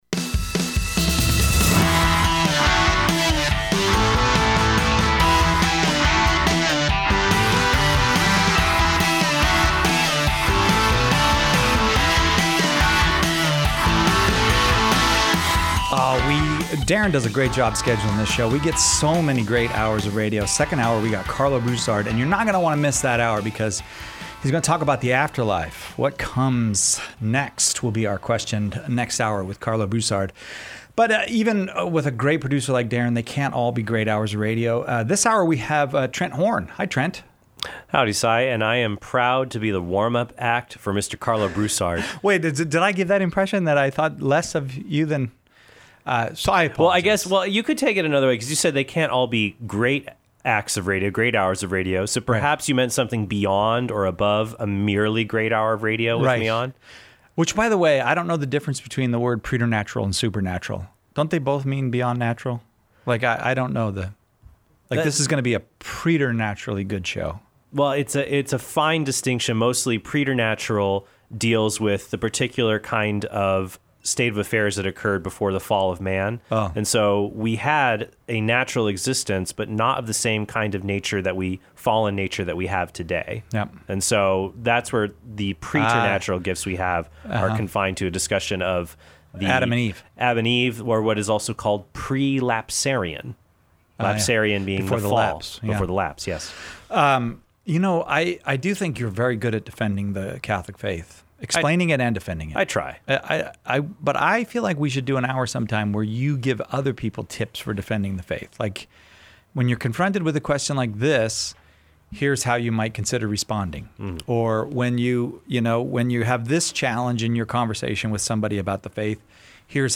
helps callers to defend and explain their faith